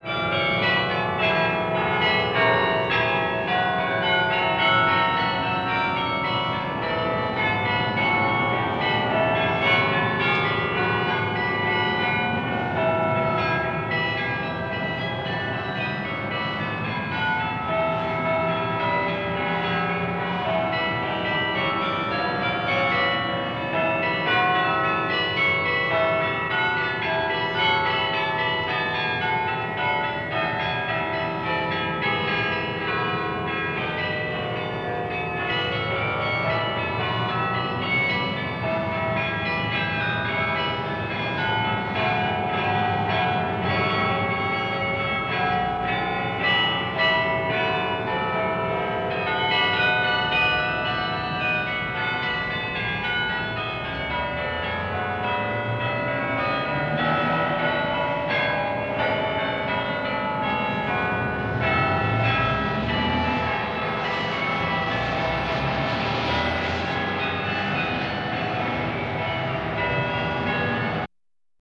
Large Church Bell Sound Effect Free Download
Large Church Bell